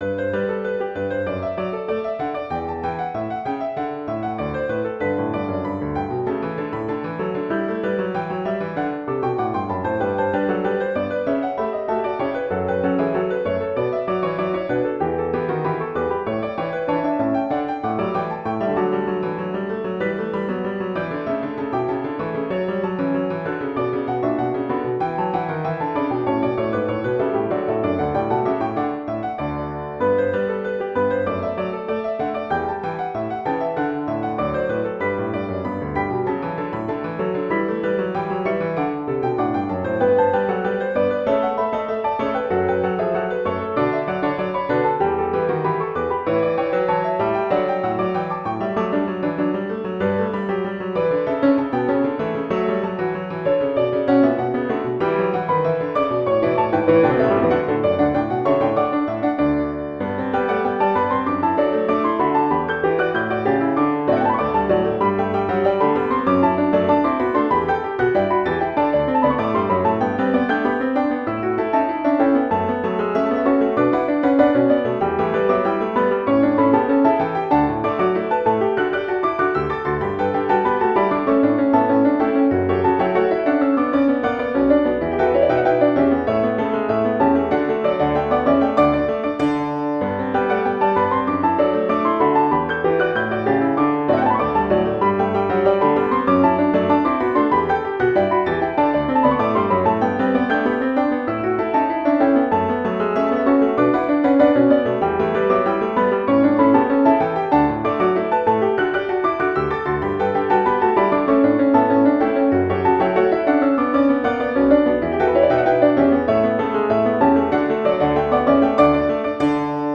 ·        Mood: light, joyful, with a bit of humor
2 Pianos